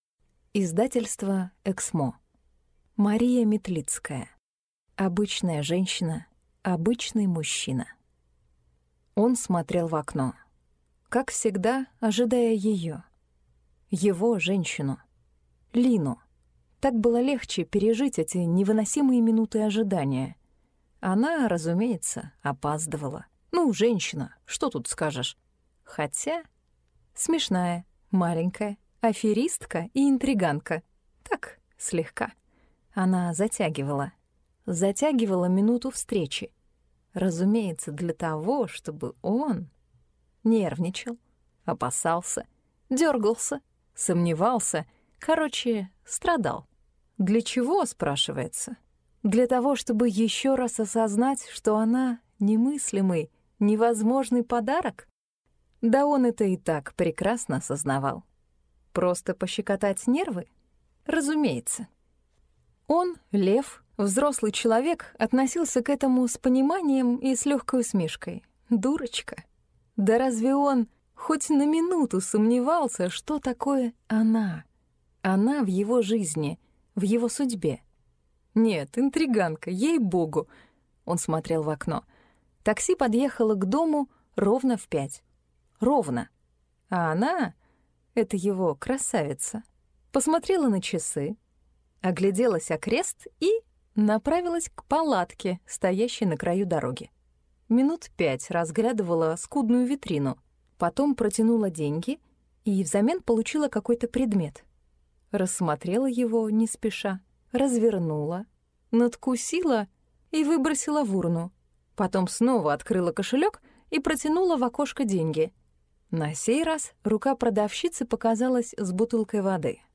Аудиокнига Обычная женщина, обычный мужчина | Библиотека аудиокниг